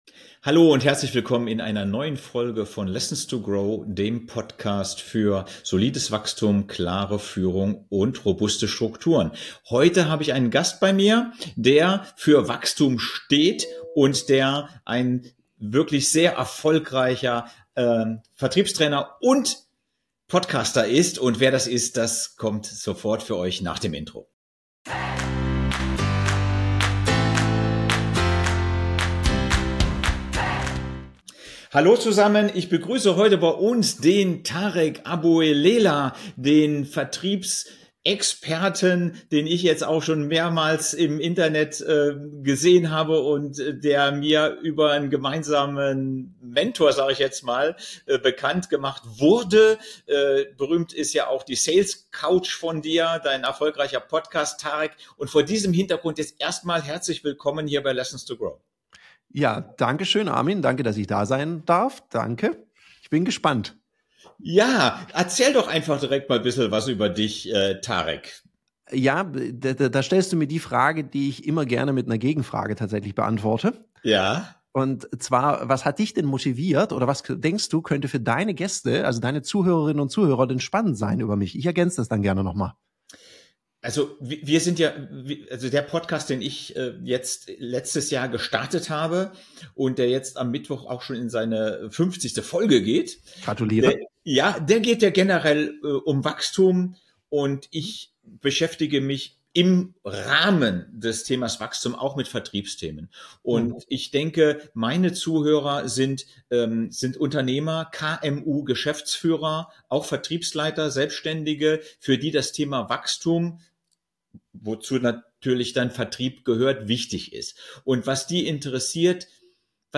Gespräch
Ein Podcast-Gespräch voller Praxiswissen, Storys aus dem Vertriebsalltag und klarer Strategien für nachhaltiges Unternehmenswachstum.